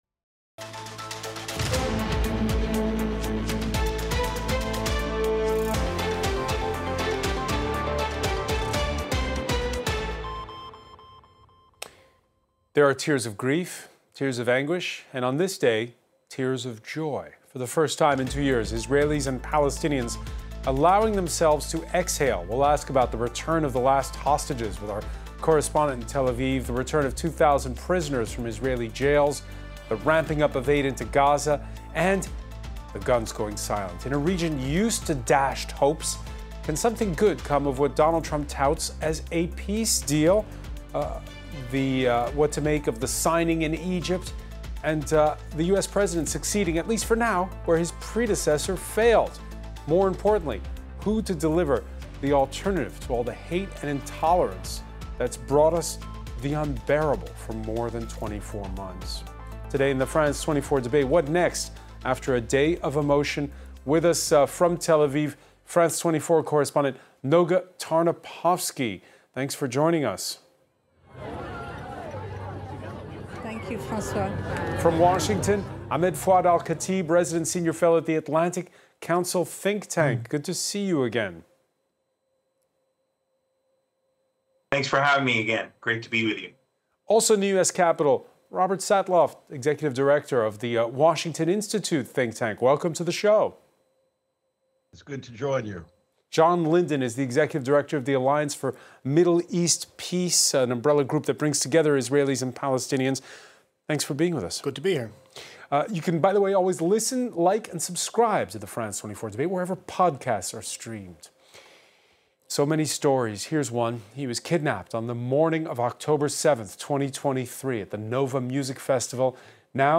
We’ll discuss the return of the last hostages with our correspondent in Tel Aviv, the release of 2,000 prisoners from Israeli jails, the ramping up of aid into Gaza, and the silencing of guns.